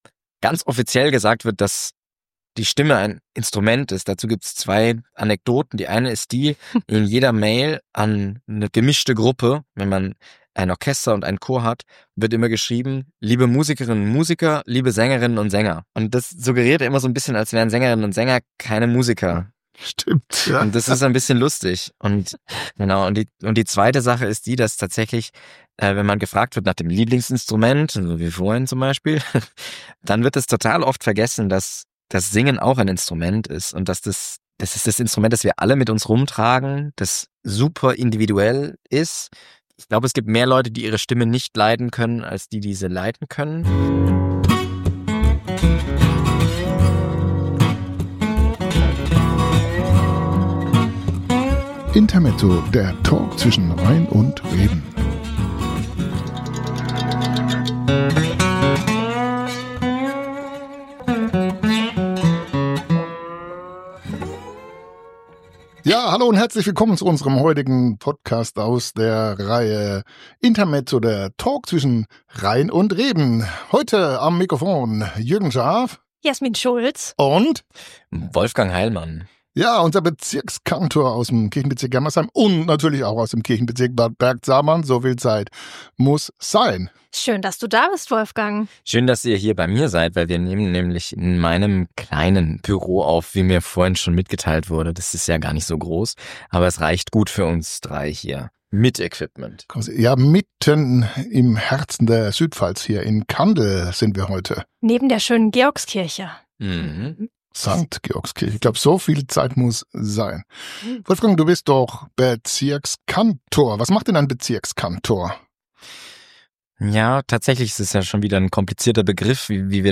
Intermezzo - der Talk zwischen Rhein und Reben.